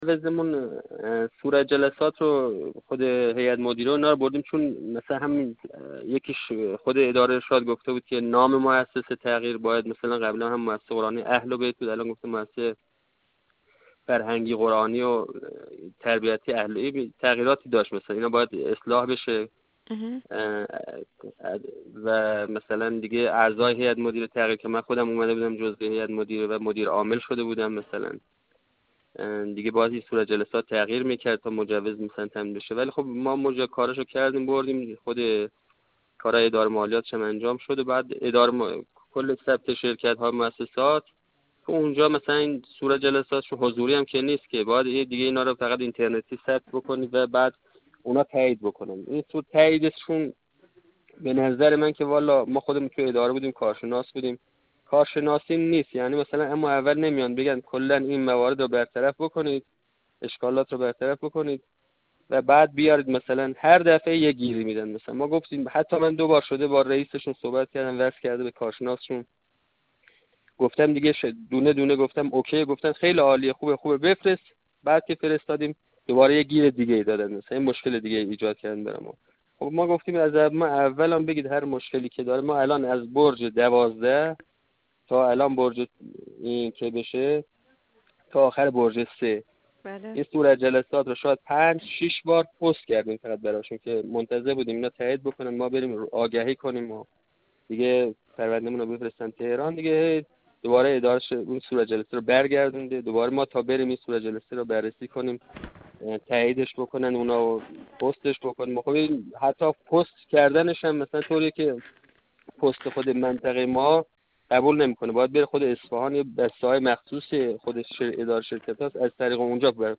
حسن‌آباد جرقویه علیا استان اصفهان در گفت‌وگو با ایکنا ضمن بیان این مطلب گفت